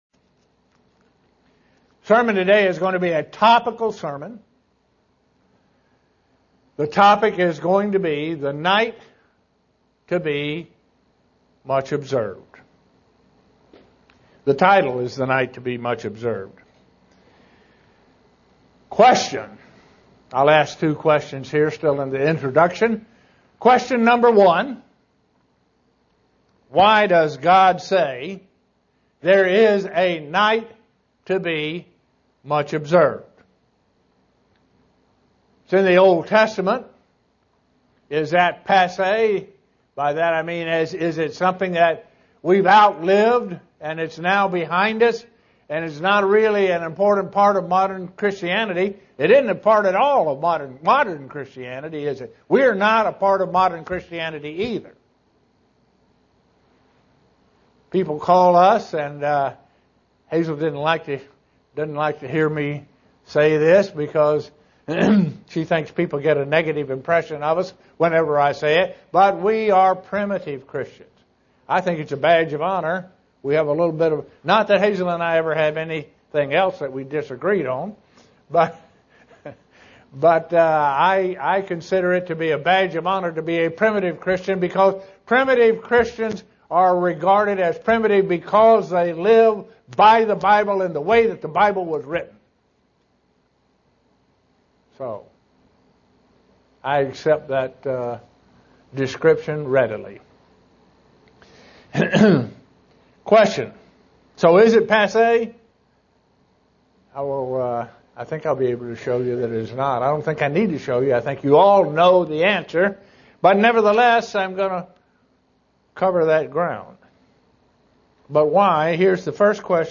Given in Buffalo, NY
SEE VIDEO BELOW UCG Sermon Studying the bible?